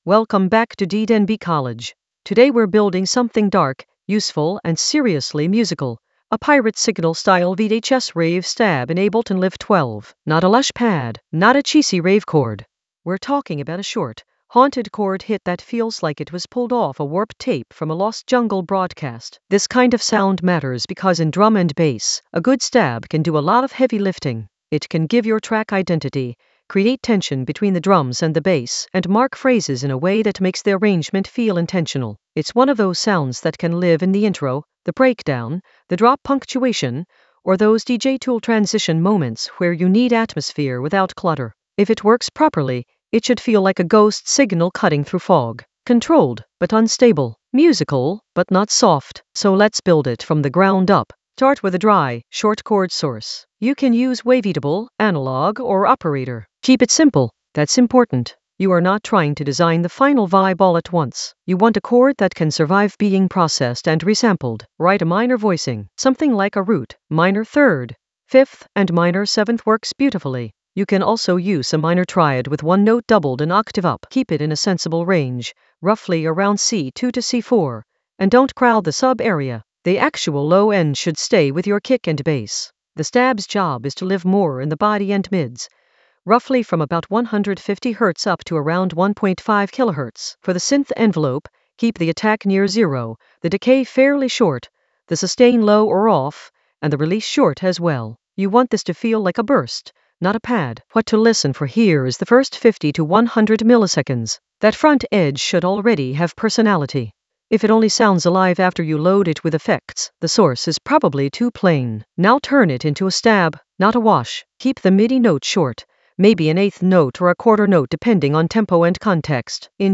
Narrated lesson audio
The voice track includes the tutorial plus extra teacher commentary.
An AI-generated advanced Ableton lesson focused on Pirate Signal Ableton Live 12 a VHS-rave stab blueprint for deep jungle atmosphere in the DJ Tools area of drum and bass production.